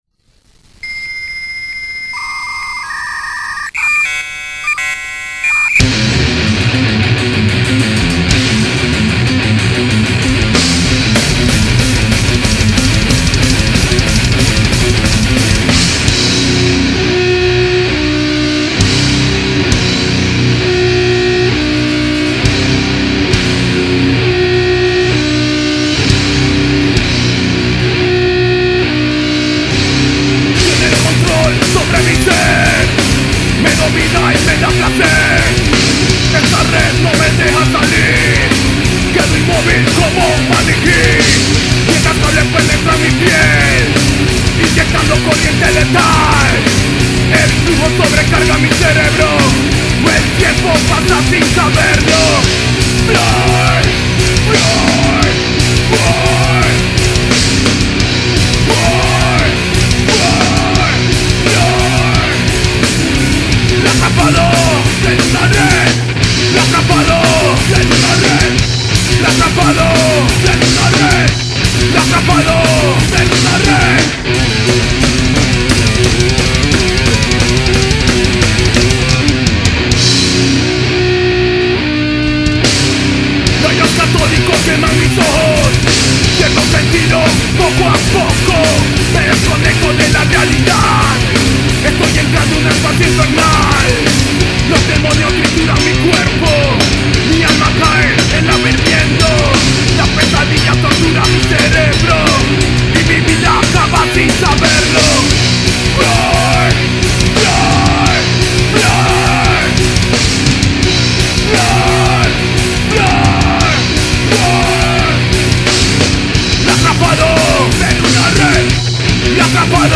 Guitarra
Bajo
Batería
Voz